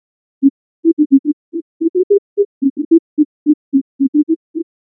For paleospectrophony, this inscription requires degridding followed by a vertical flip to orient it like a modern sound spectrogram.
Paleospectrophony doesn't treat these marks as C, D, E, F, G, and A, but as pieces of acoustic data at specific distances between the bottom (set to B=246.94 Hz) and top (set an octave up, at 493.88 Hz).
As a result, the intervals sound somewhat "off." But the melody is still quite recognizable.